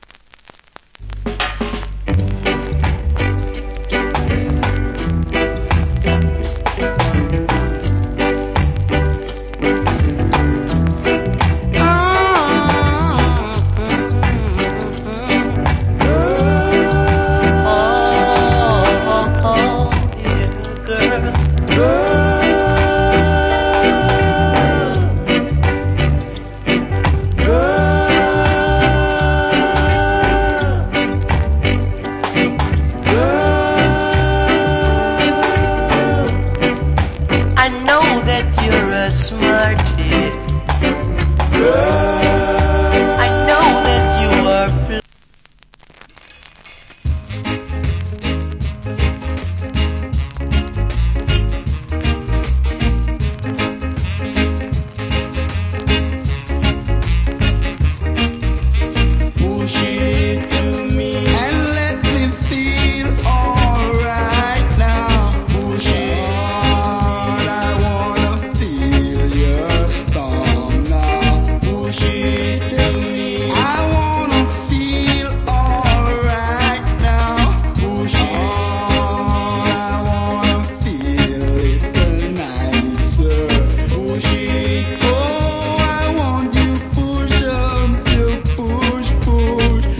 Cool Rock Steady Vocal.